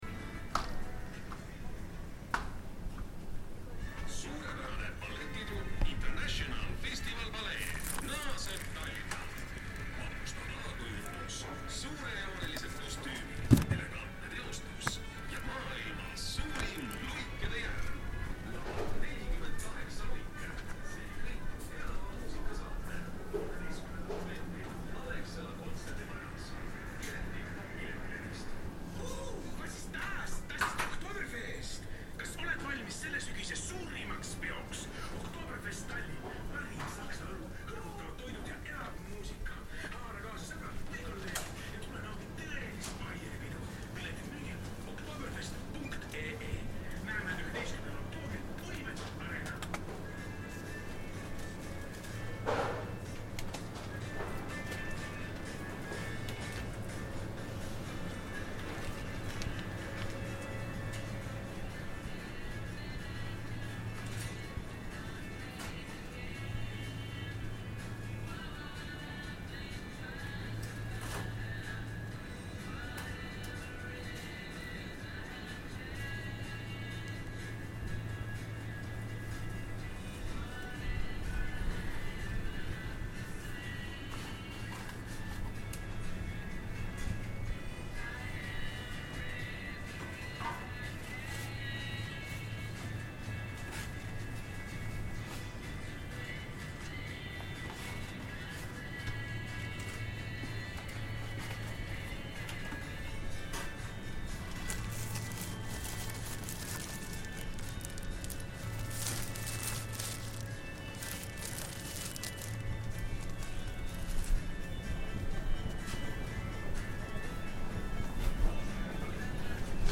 Shopping in a Rimi supermarket in Tallinn, with Estonian announcements and promos, piped pop songs, and the sound of beeps as we check out our purchases at the end.